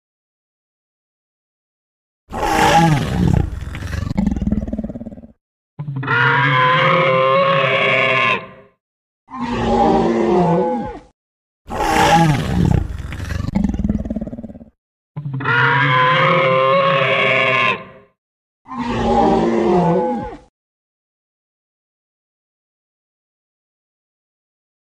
دانلود آهنگ دایناسور 1 از افکت صوتی انسان و موجودات زنده
دانلود صدای دایناسور 1 از ساعد نیوز با لینک مستقیم و کیفیت بالا
جلوه های صوتی